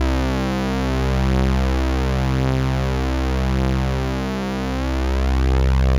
C3_trance_lead_1.wav